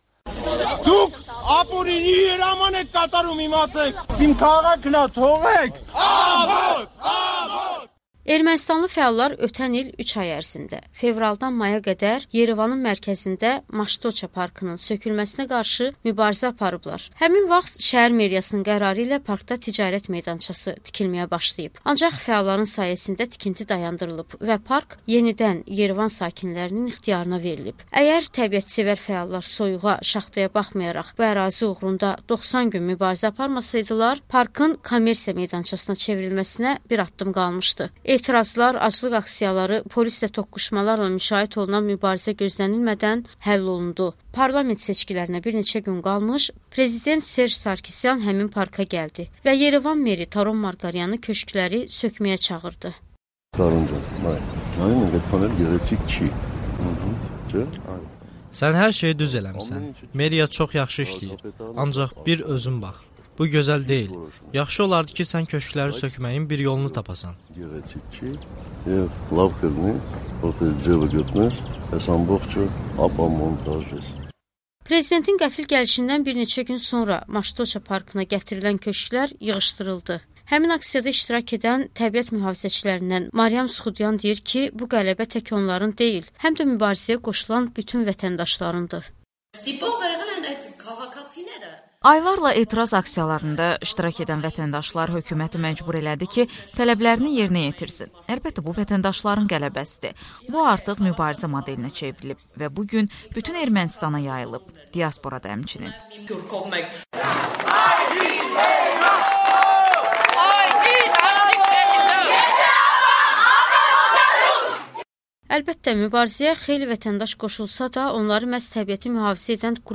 Parkı söküntüdən qoruyan erməni fəallar danışır